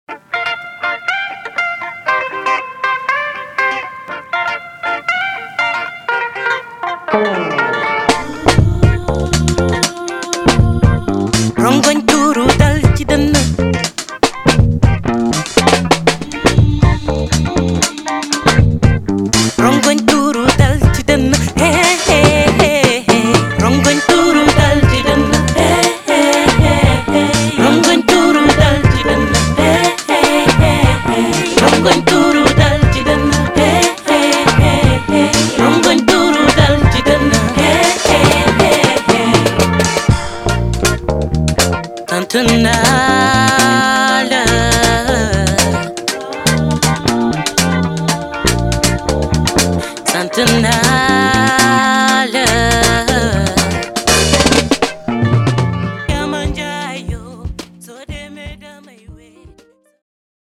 Afropop & Afrobeats